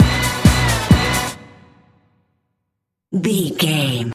Fast paced
Ionian/Major
Fast
synthesiser
drum machine
80s